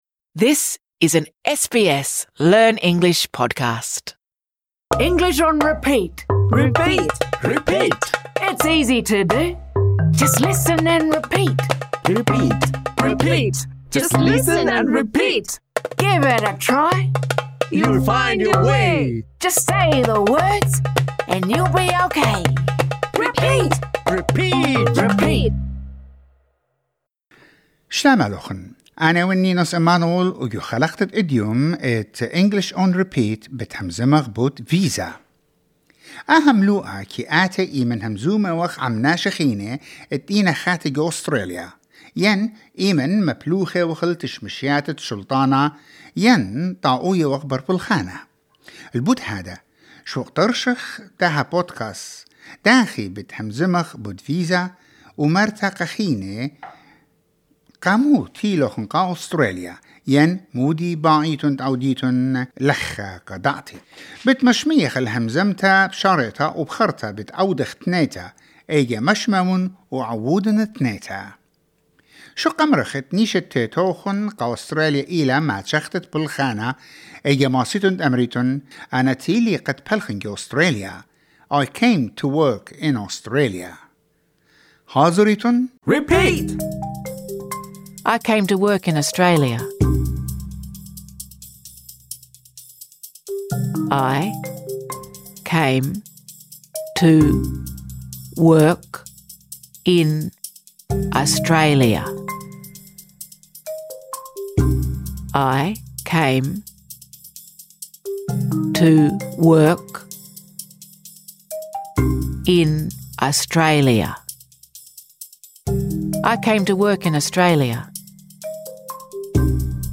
This lesson is designed for easy-level learners. In this episode, we practise saying the following phrases: I came to work in Australia.